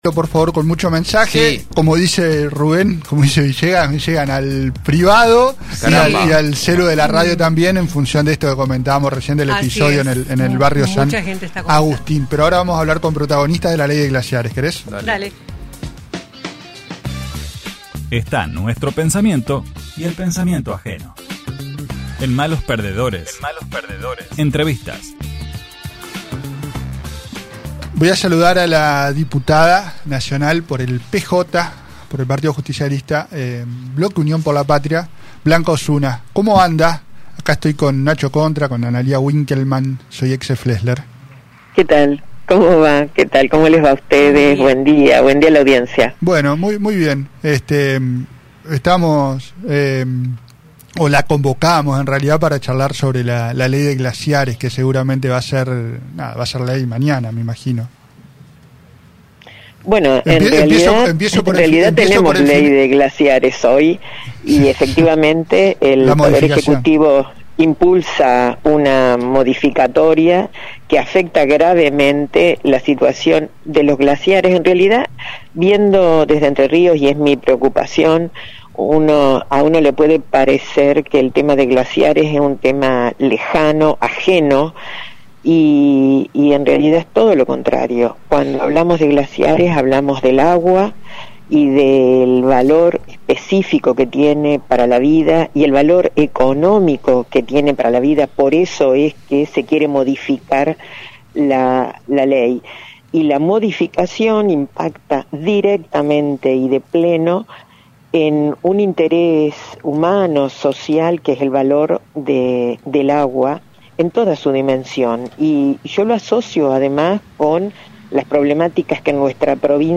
Durante una entrevista con el programa Malos Perdedores